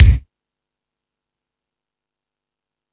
Lotsa Kicks(52).wav